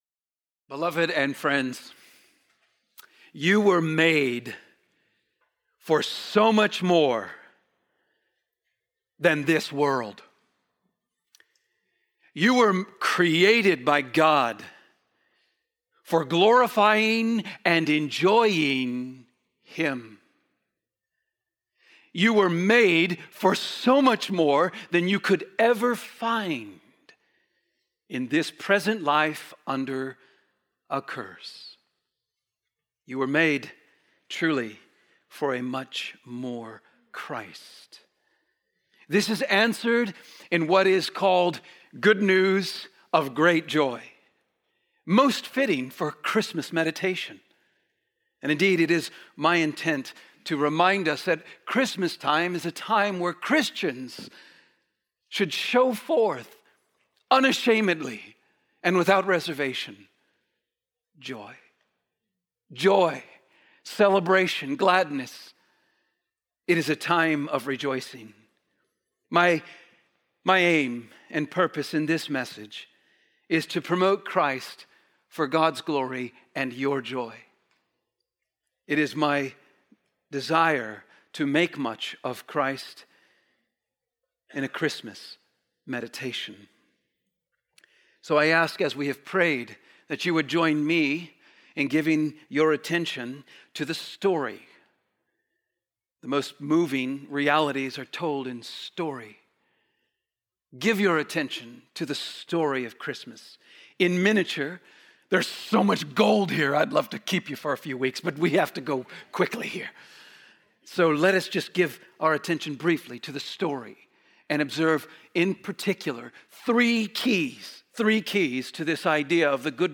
Sermons - Trinity Bible Church